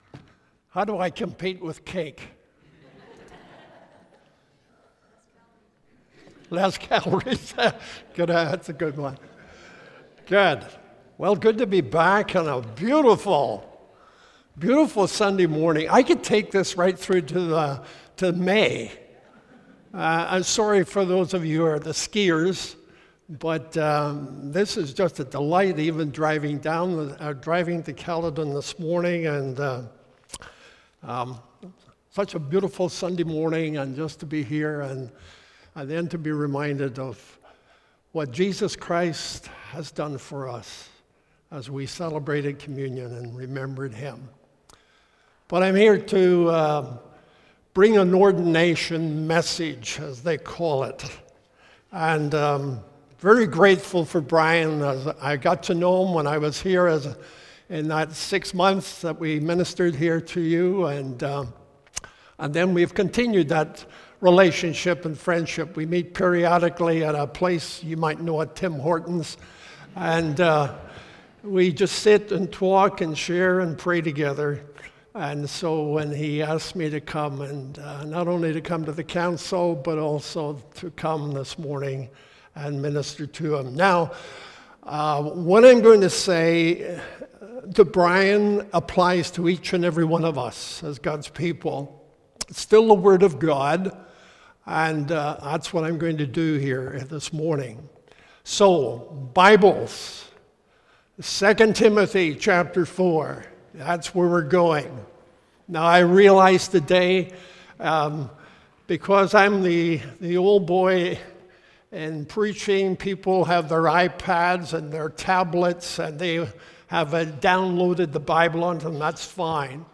Ordination Service